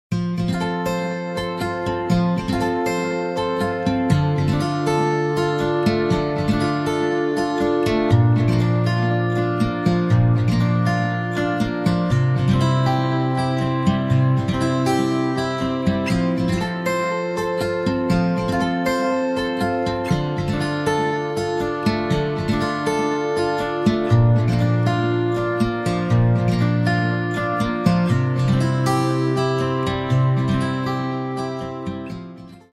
48 Akkordkarten für die Gitarre.